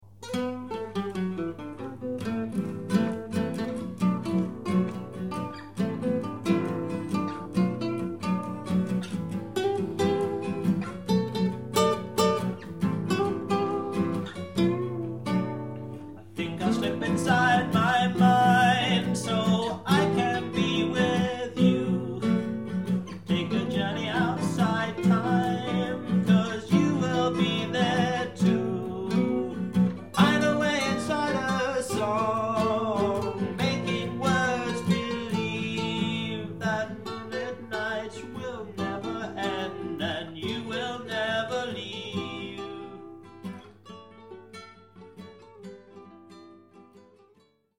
UK "Contemporary folk" band: 1967 - 1974